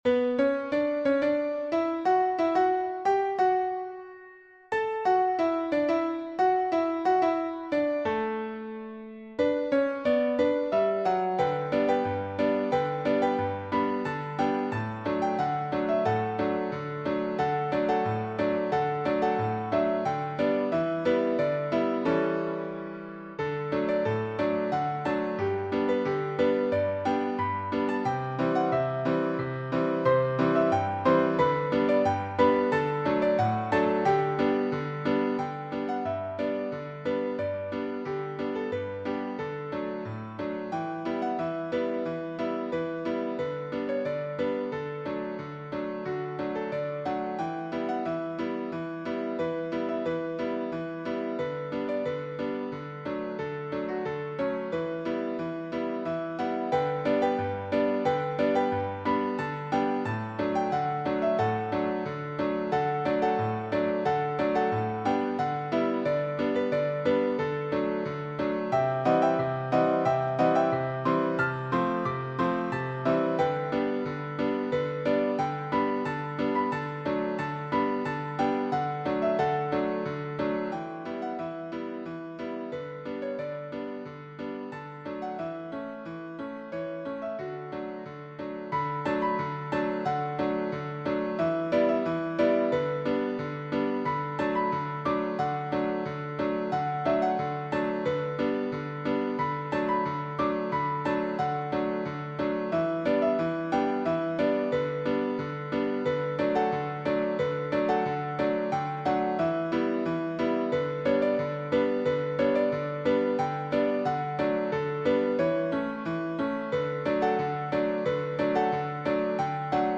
Joyous
Classic ragtime
Piano only